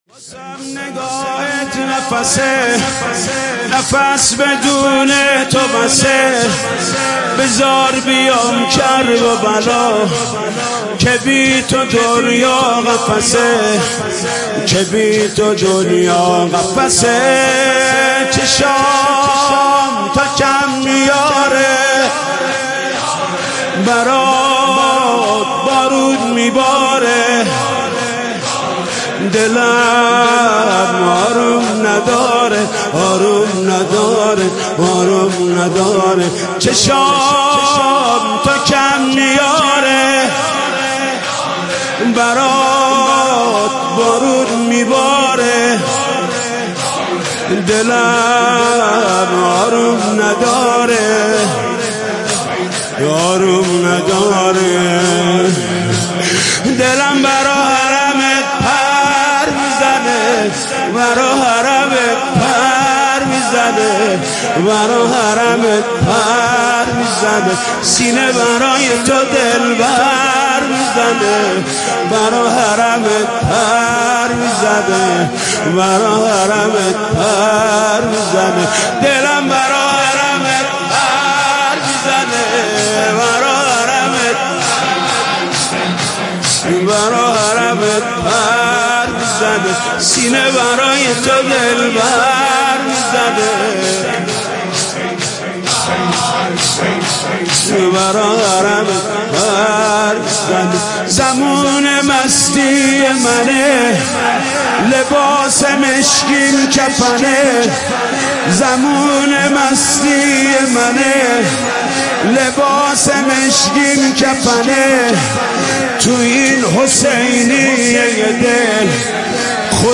مداحی جدید حاج محمود کريمی شب اول محرم97 هيأت راية العباس